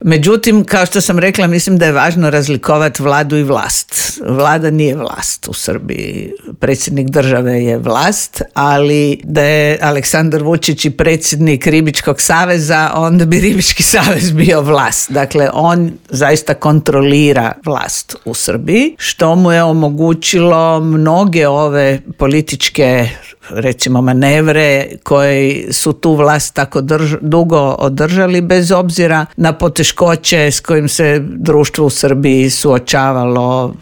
U Intervjuu Media servisa ugostili smo bivšu ministricu vanjskih i europskih poslova Vesnu Pusić koja kaže da je civilno društvo u Srbiji pokazalo da tamo postoji demokratska javnost što se poklopilo sa zamorom materijala vlasti: